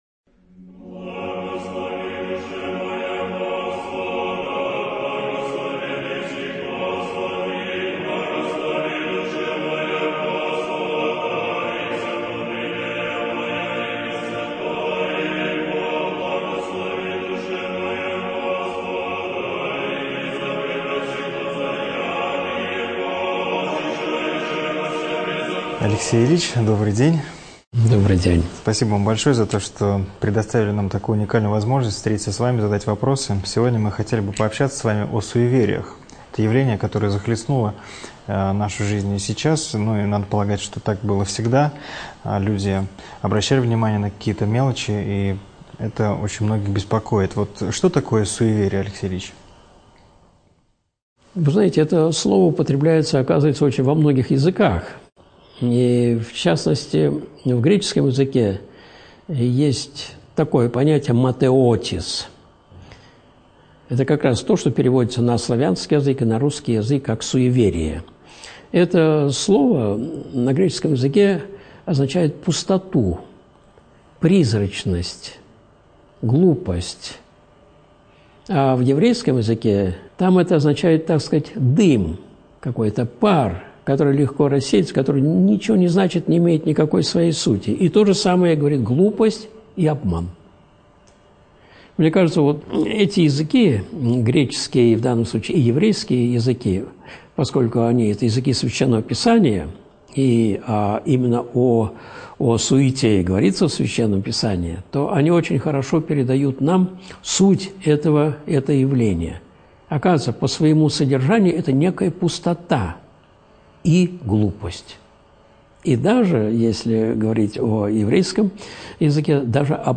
Видеолекции